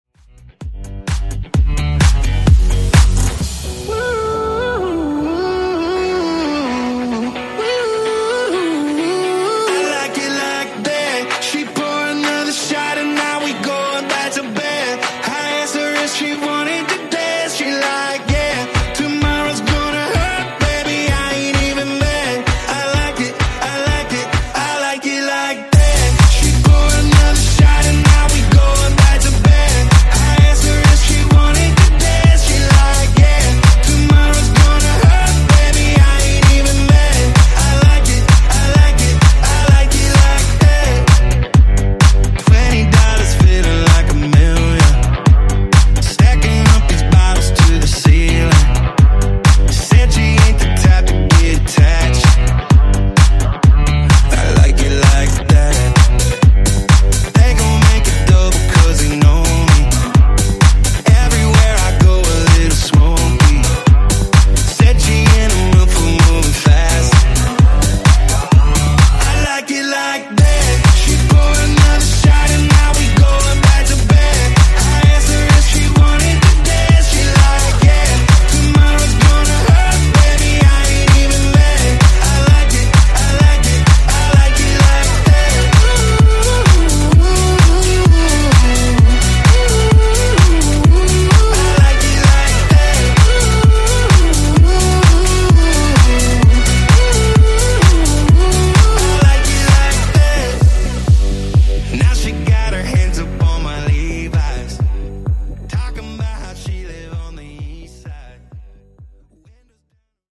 Genres: DANCE , EDM , RE-DRUM
Clean BPM: 129 Time